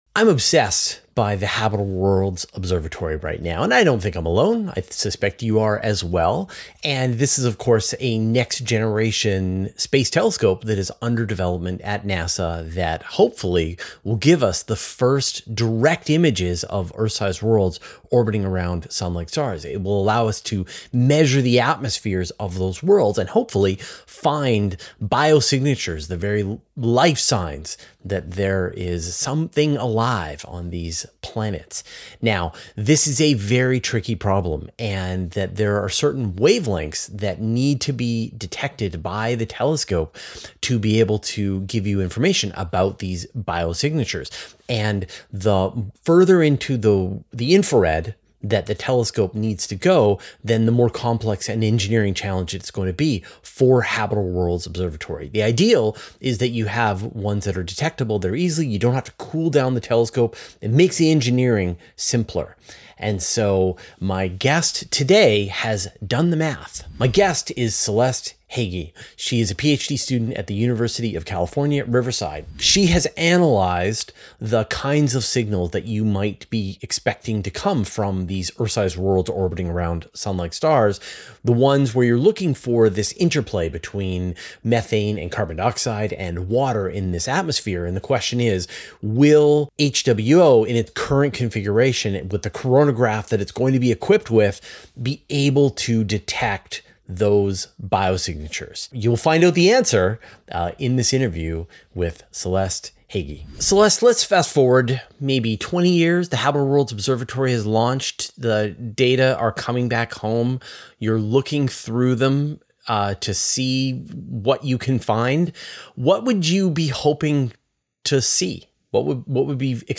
Finding out the answers in this interview.